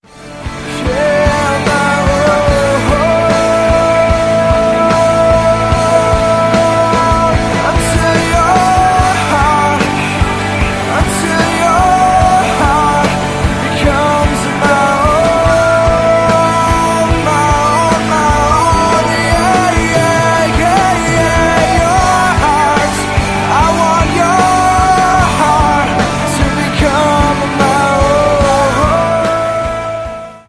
live worship
• Sachgebiet: Praise & Worship